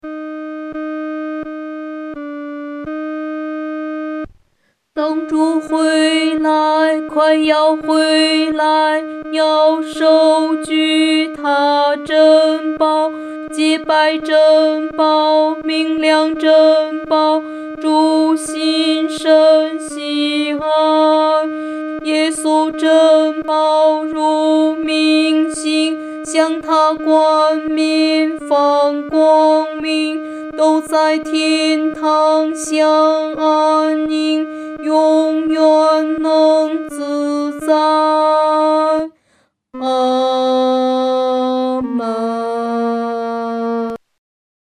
独唱（女低）